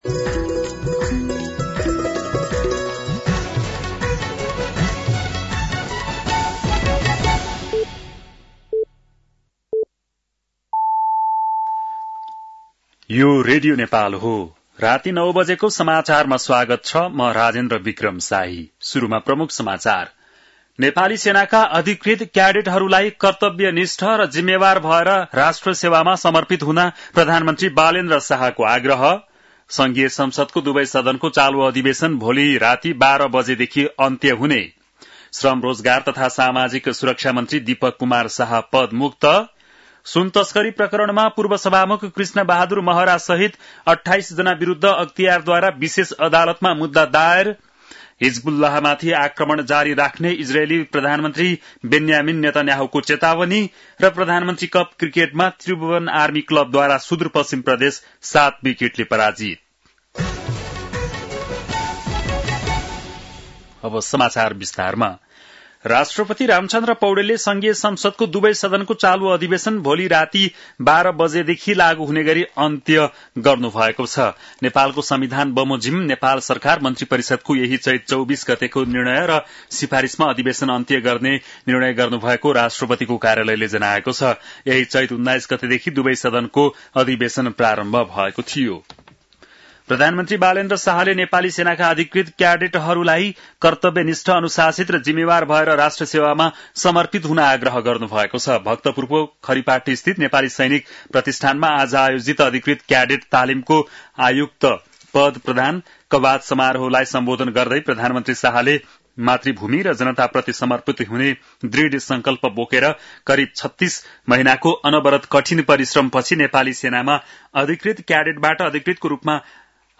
बेलुकी ९ बजेको नेपाली समाचार : २६ चैत , २०८२